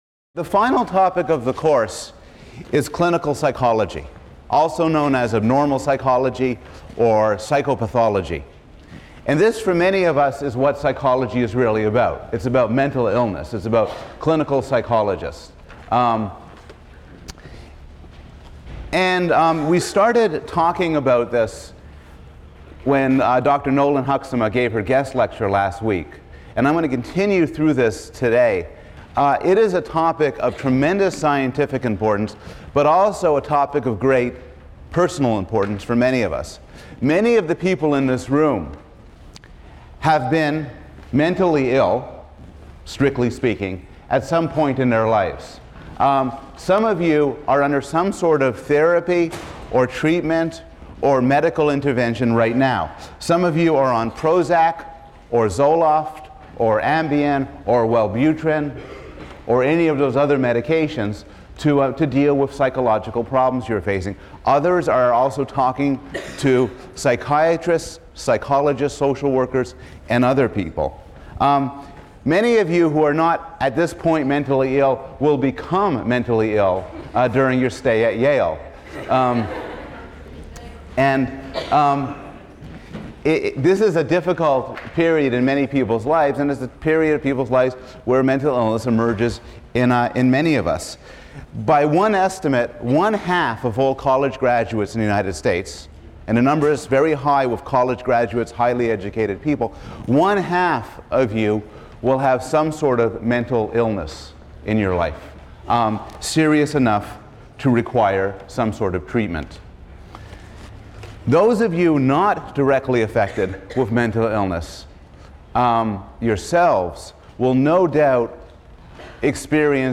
PSYC 110 - Lecture 19 - What Happens When Things Go Wrong: Mental Illness, Part II | Open Yale Courses